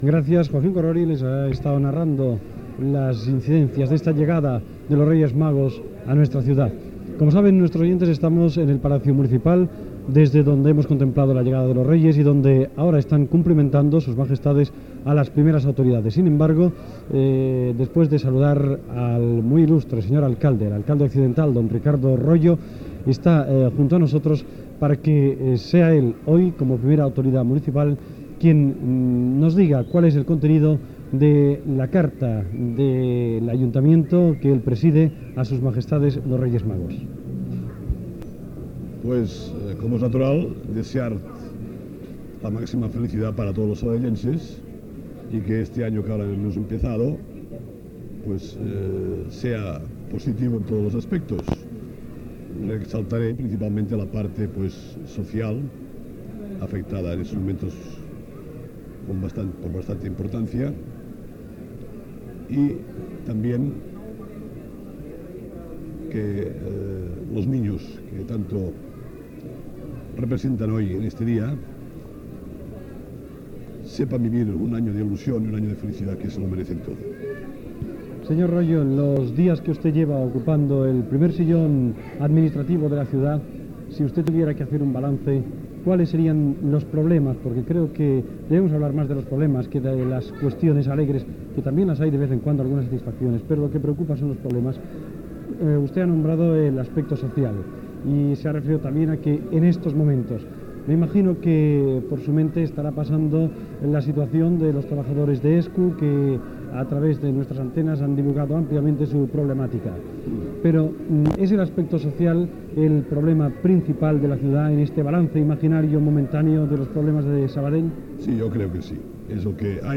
Transmissió, des de l'Ajuntament de Sabadell, de l'arribada dels Reis de l'Orient, amb declaracions de l'alcadel accidental Ricardo Royo
Informatiu